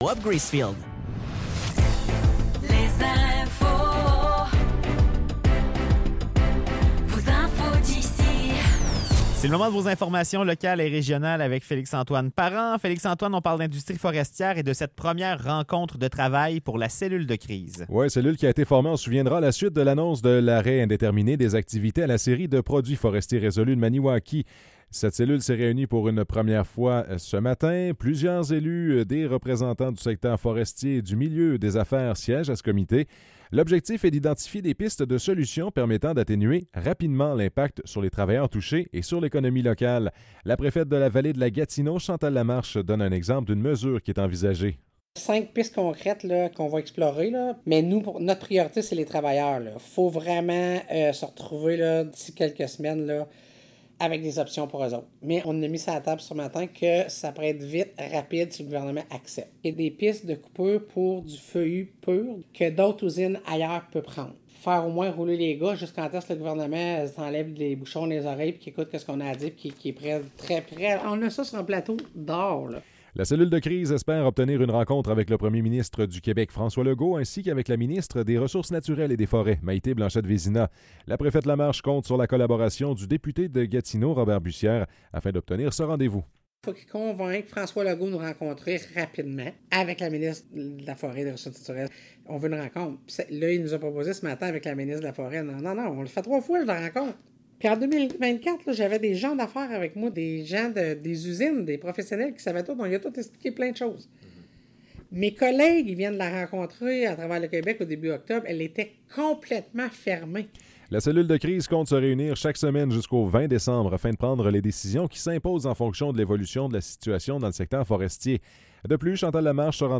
Nouvelles locales - 18 octobre 2024 - 16 h